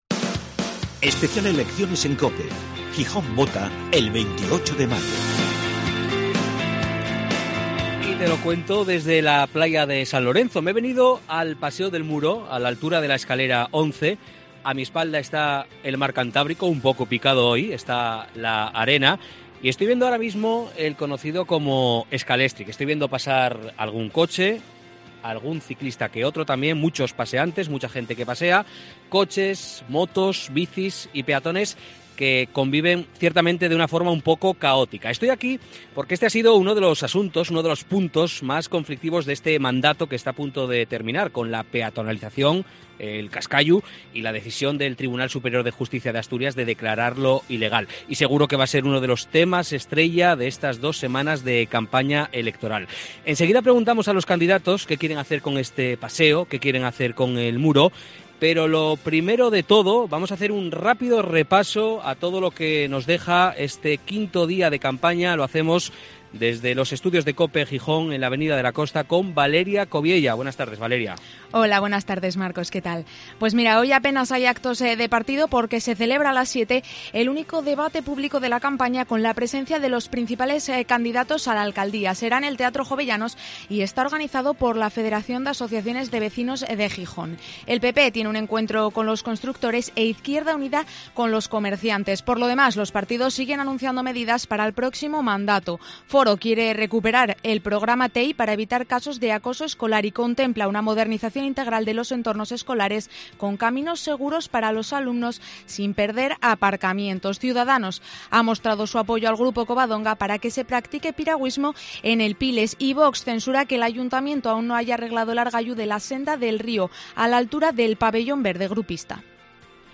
En COPE hablamos con los candidatos, pero también escuchamos a los ciudadanos, sus inquietudes y peticiones. Escucha el Especial Elecciones Municipales en COPE Gijón, desde el Muro de San Lorenzo: las propuestas de los partidos para el paseo paralelo a la playa y la avenida de Rufo García Rendueles.